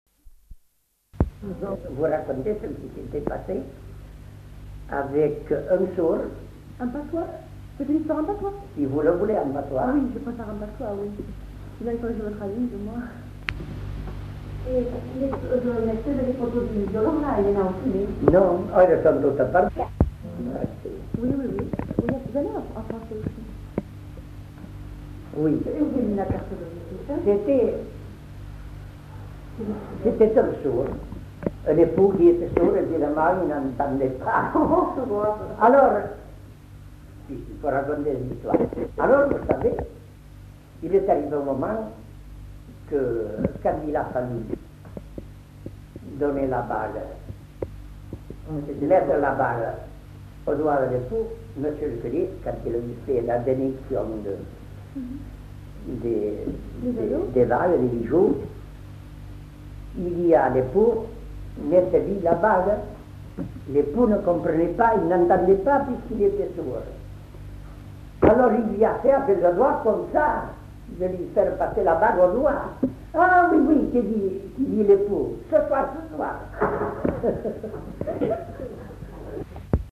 Lieu : Haut-Mauco
Genre : conte-légende-récit
Effectif : 1
Type de voix : voix d'homme
Production du son : parlé